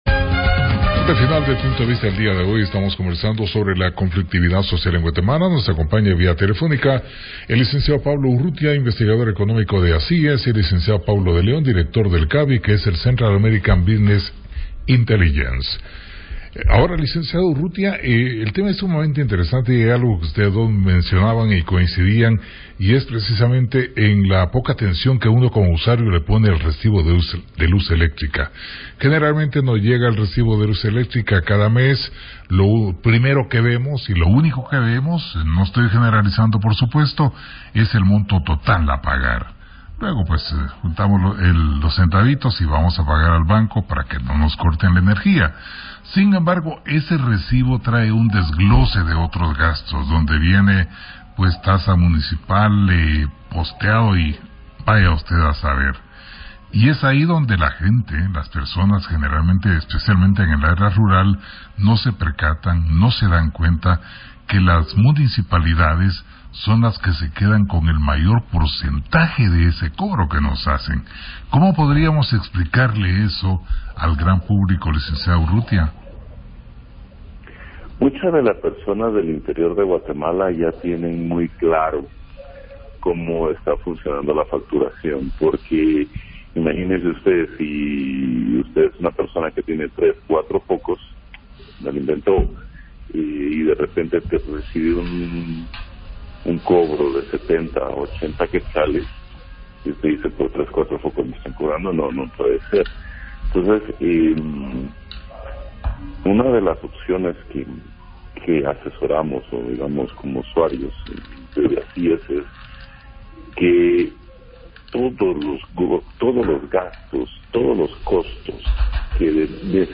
PUNTO DE VISTA / RADIO PUNTO: Entrevista